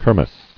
[kir·mess]